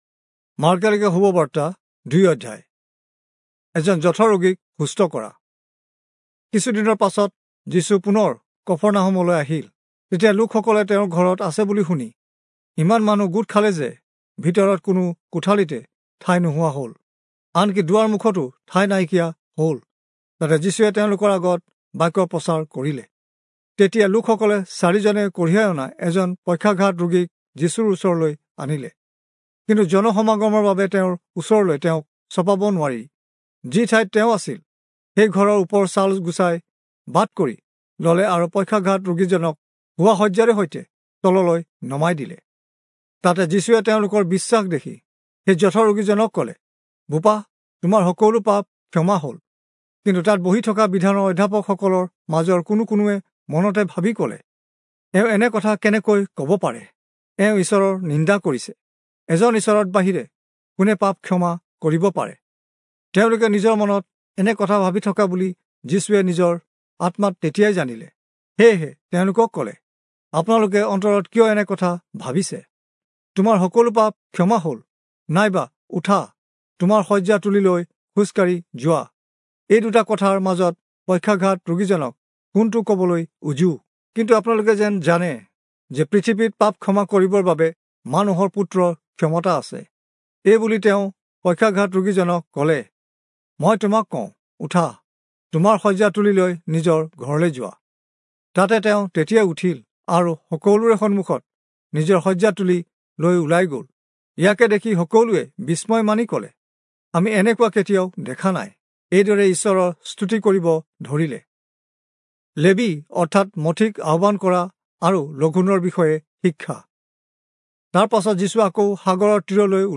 Assamese Audio Bible - Mark 5 in Irvkn bible version